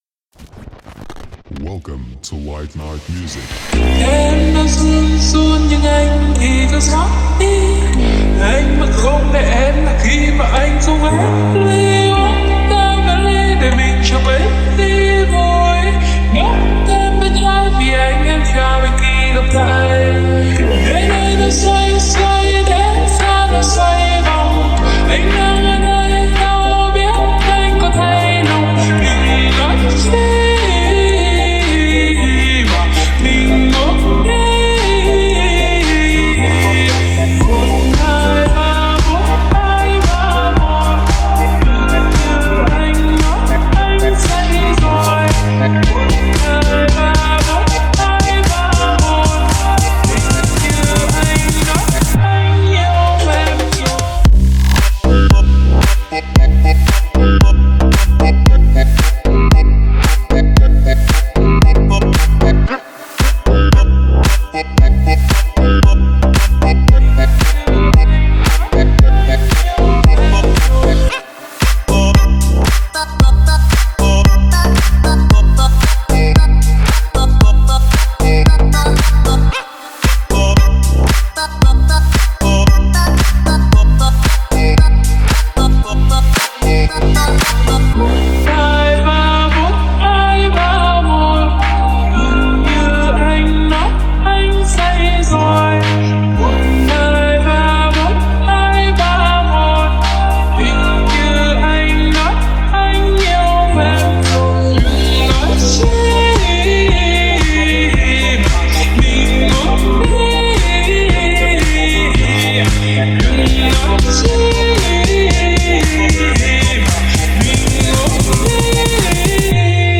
свежие звучания и яркие синтезаторы